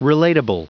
Prononciation du mot relatable en anglais (fichier audio)
Prononciation du mot : relatable